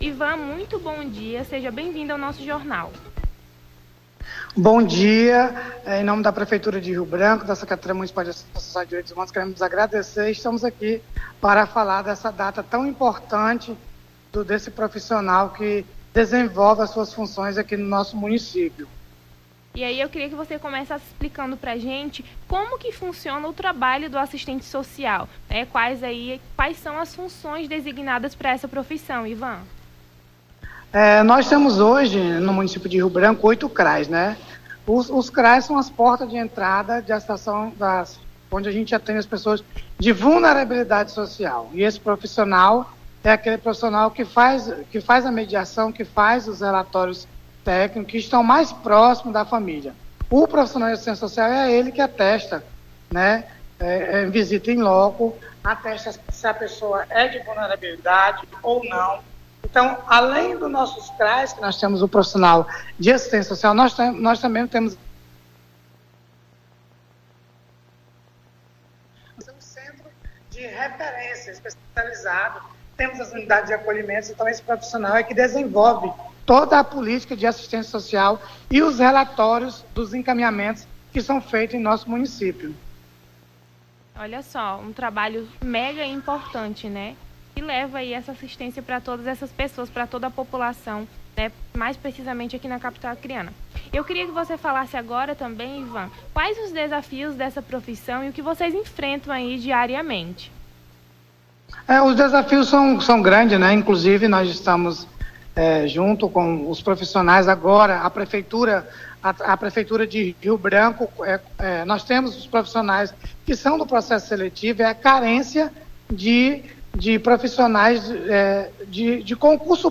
Nome do Artista - CENSURA - ENTREVISTA (DIA DA ASSISTENTE SOCIAL DESAFIOS E CONTRIBUIÇOES) 06-12-23.mp3